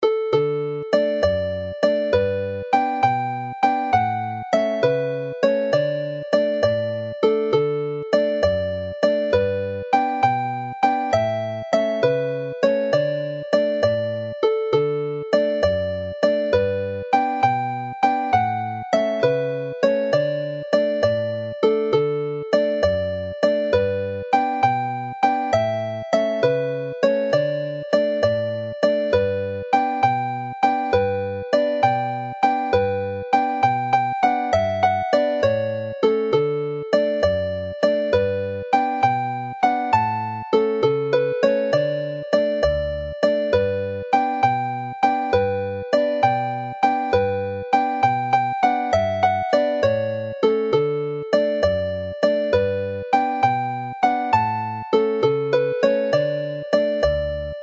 closes the set in a more cheerful mood.
Play slowly